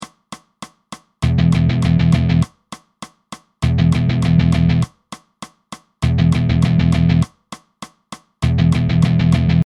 They should be palm muted.
Alternate picking
Down picking